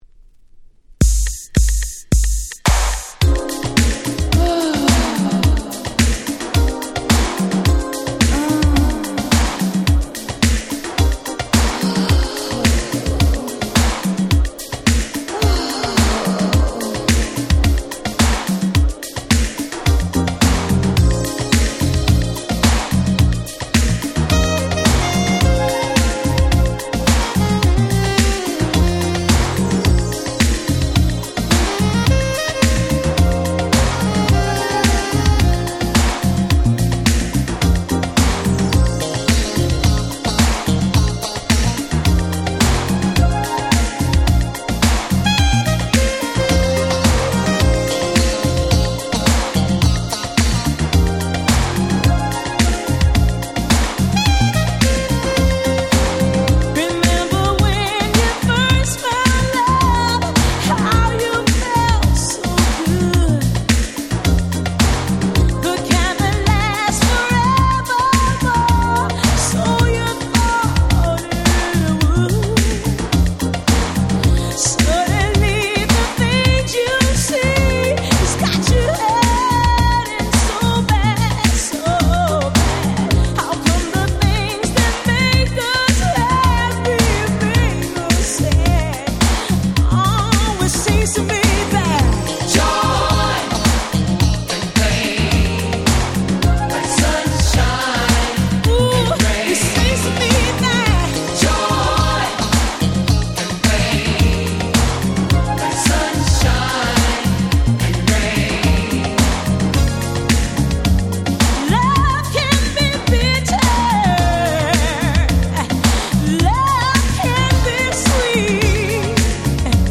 88' Super Nice Cover R&B / ブラコン！！